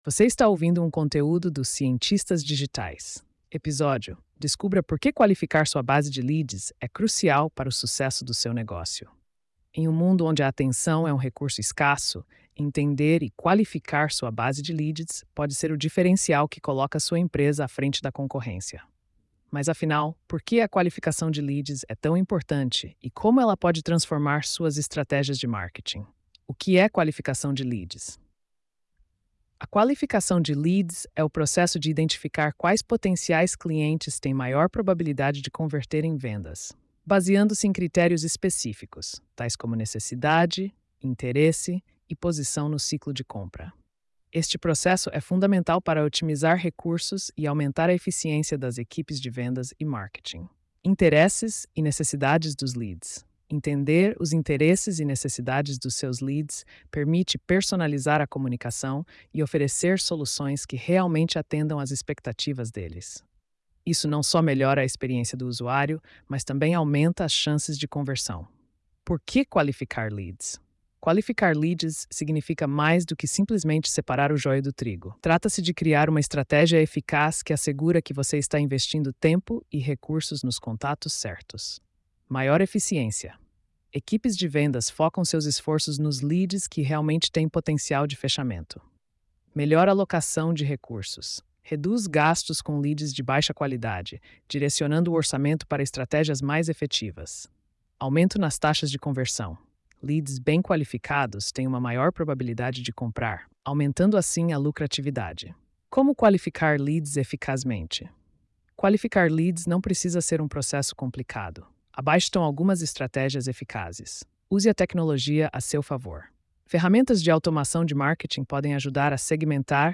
post-3181-tts.mp3